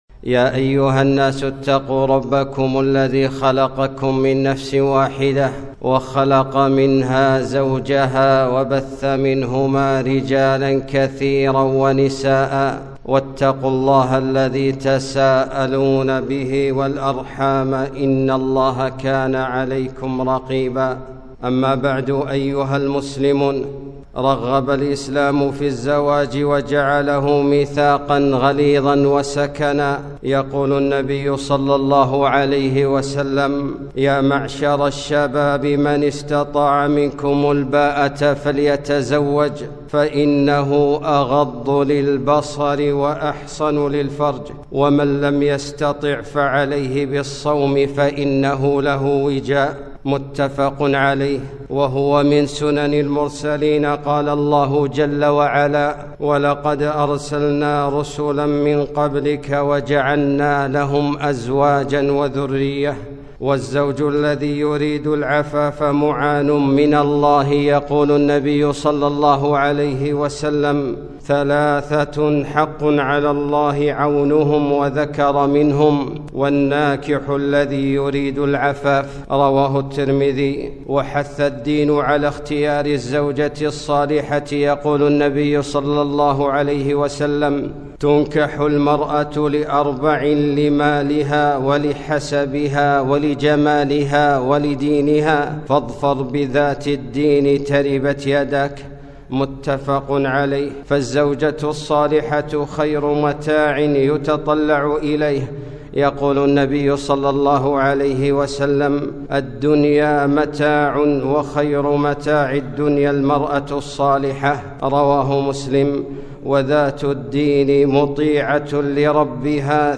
خطبة - العزوف عن الزواج